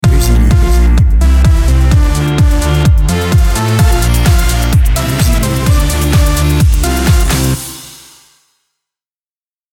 BPM Rapide